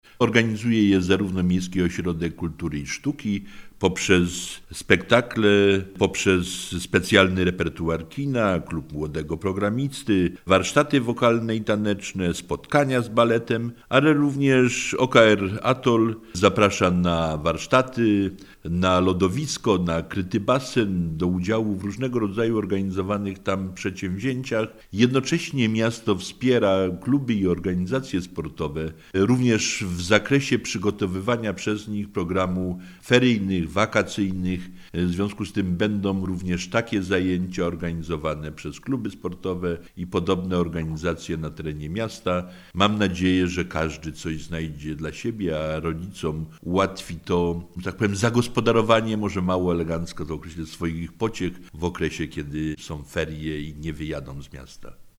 Co dla nich przygotowano? Rozmawiamy z Janem Bronsiem, burmistrzem Oleśnicy.